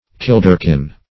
Kilderkin \Kil"der*kin\, n. [OD. kindeken, kinneken, a small